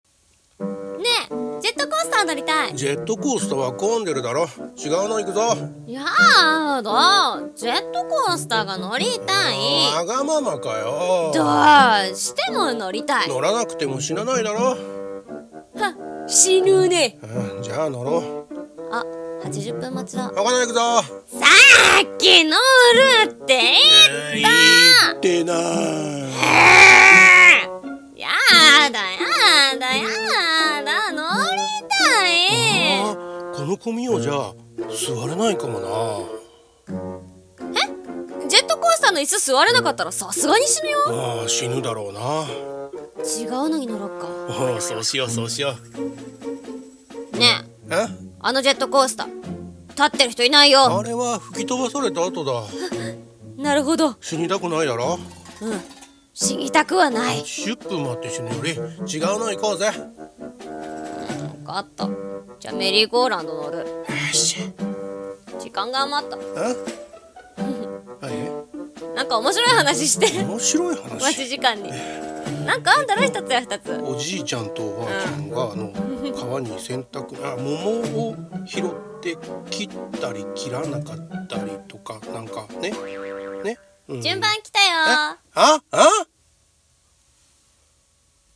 【声劇】ジェットコースターに乗りたい